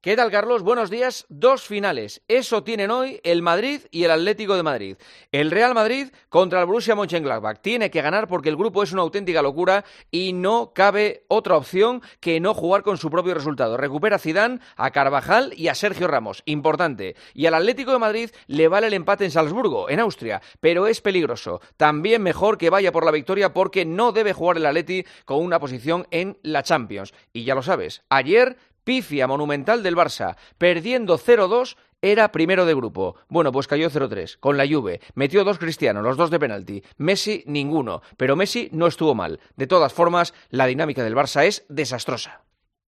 El comentario de Juanma Castaño
Juanma Castaño analiza la actualidad deportiva en 'Herrera en COPE'